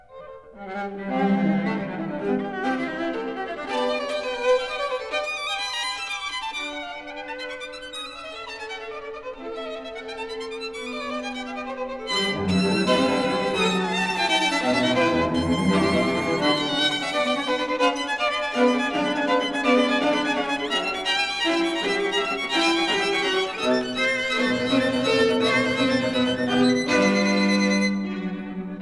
Studios,30th Street, New York City